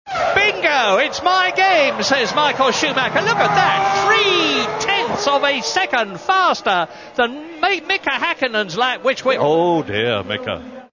Hear Murray change down a gear as he spots a despondent Hakkinen